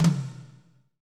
Index of /90_sSampleCDs/Northstar - Drumscapes Roland/DRM_Pop_Country/KIT_P_C Wet 1 x
TOM P CHI1IL.wav